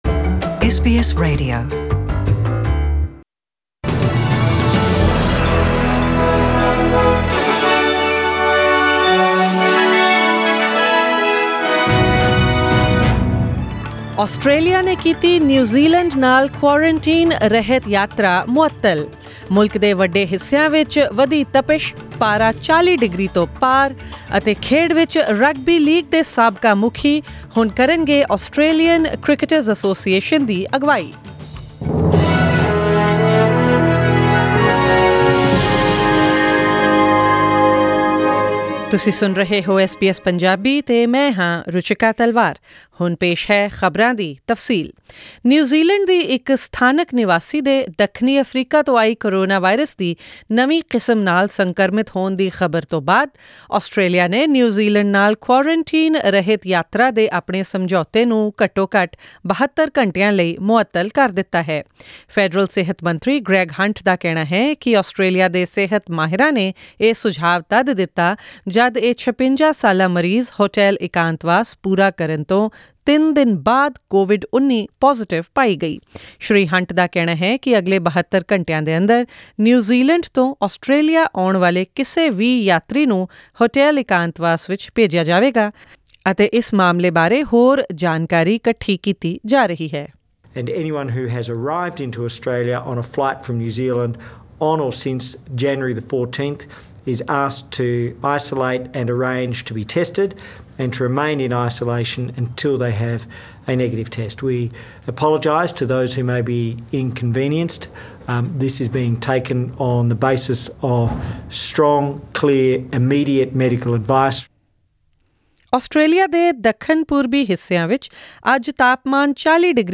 Australian News in Punjabi 25 January 2021: Was today too hot to handle? Tomorrow will be hotter as heatwave continues in Australia
The threat of bushfires rages unabated in South Australia. This and more, along with forex rates, sports updates and the weather forecast in the bulletin tonight.